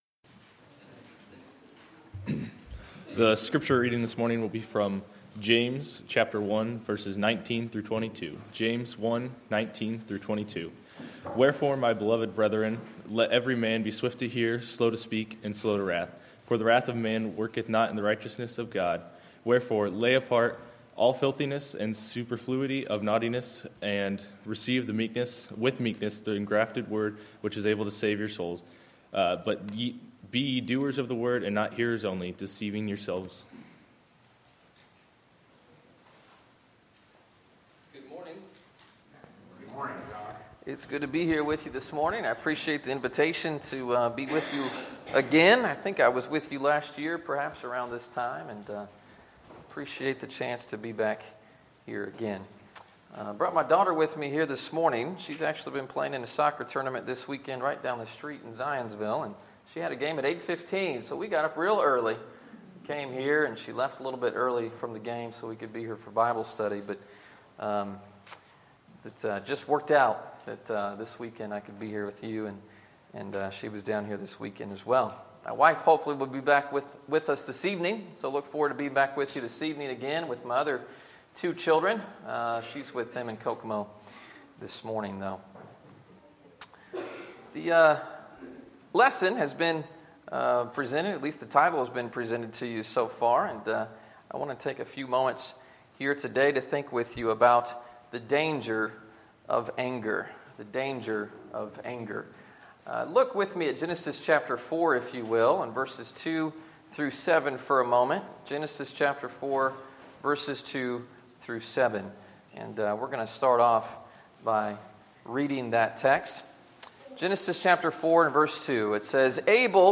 Sermons, October 9, 2016